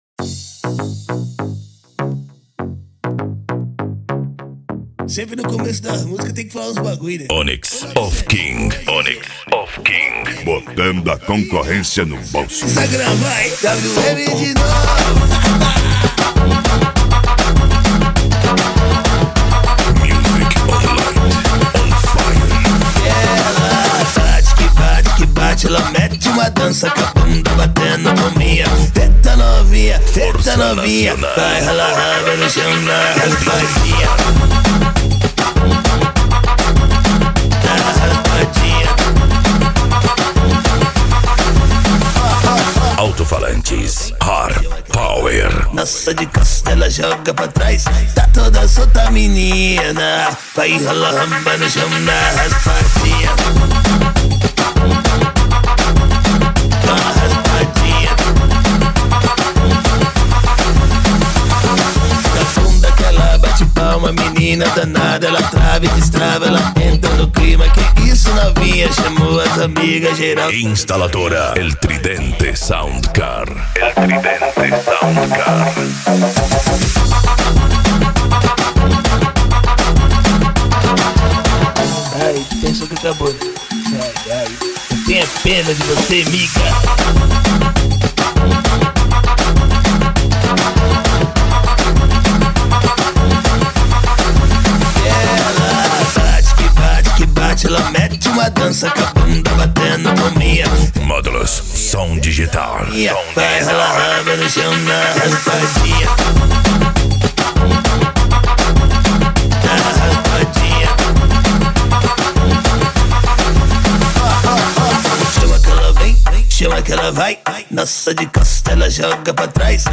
Bass
Modao
Musica Electronica
Remix